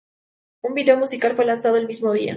vi‧de‧o
Pronunciado como (IPA)
/biˈdeo/